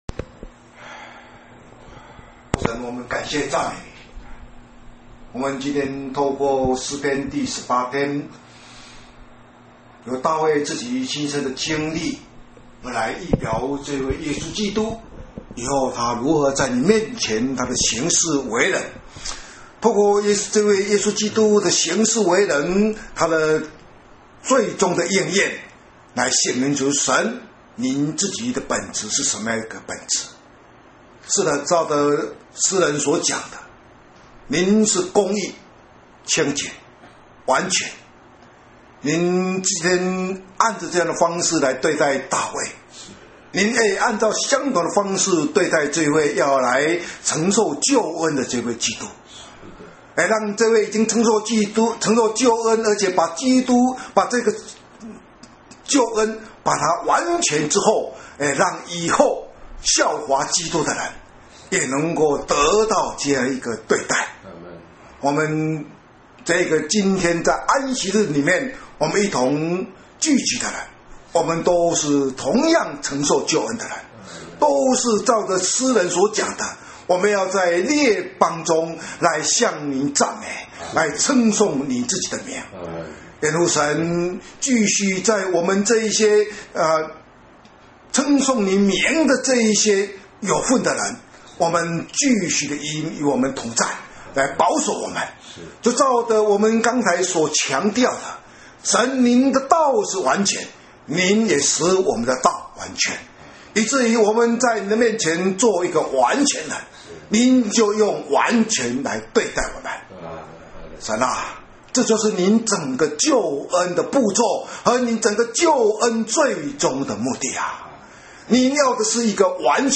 （詩篇 18）禱告